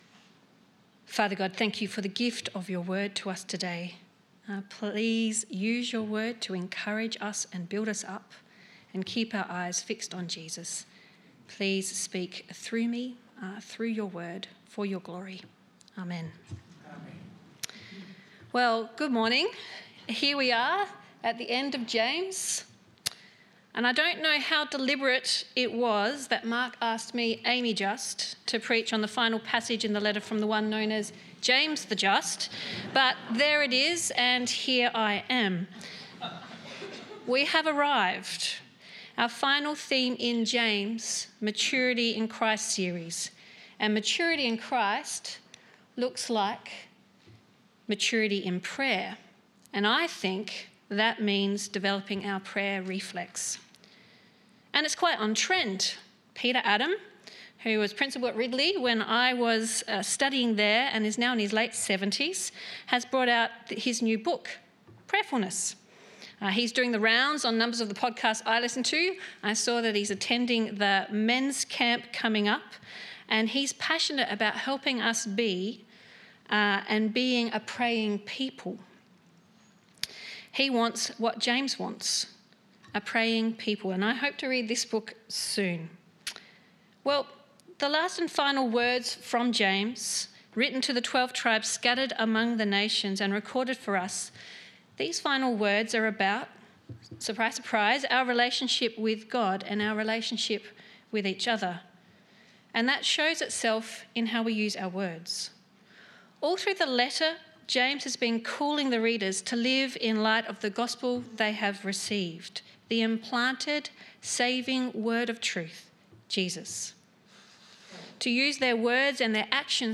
Sermons | St Alfred's Anglican Church
Guest Speaker